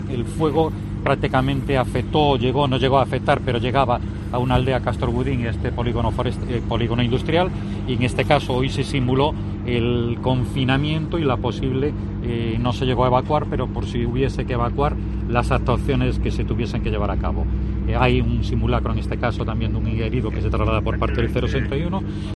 El director xeral de Emerxencias define la recreación de incendio en Caldas